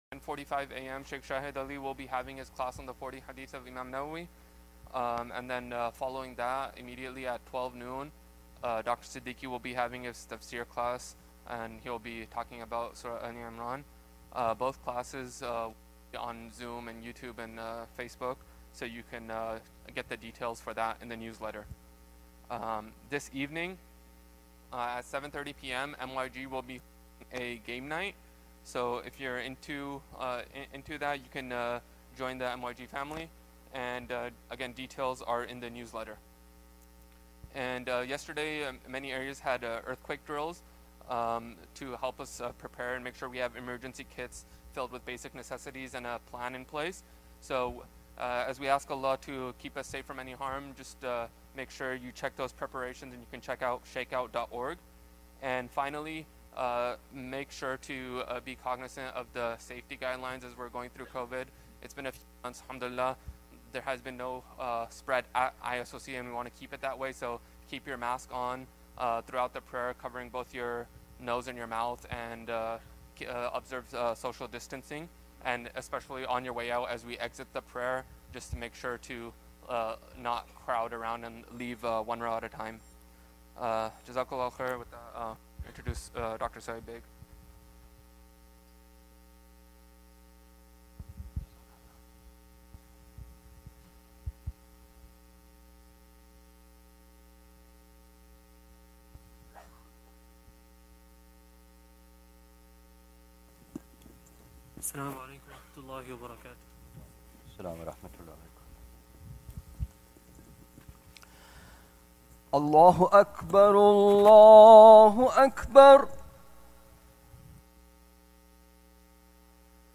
Friday Khutbah - "Relying Upon Allah"